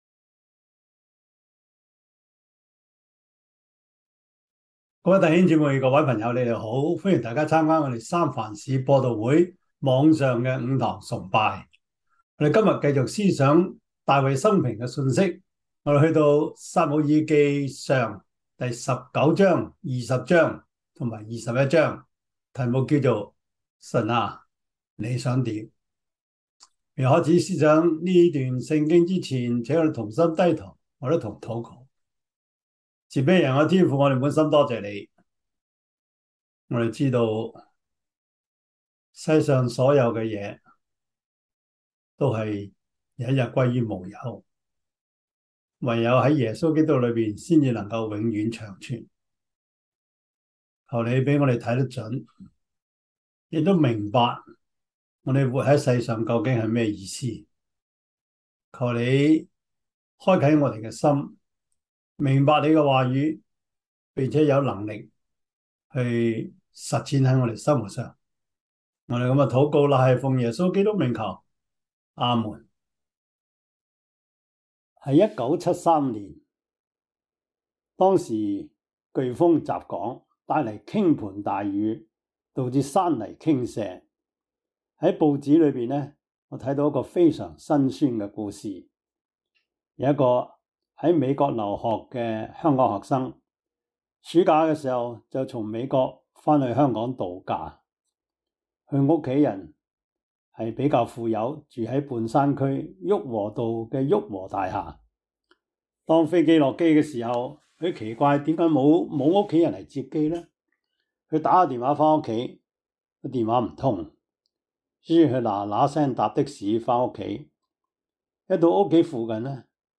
21:10-15 Service Type: 主日崇拜 撒 母 耳 記 上 19:11-18 Chinese Union Version
Topics: 主日證道 « 自隱的神 把別人介紹給主 – 第十四課 »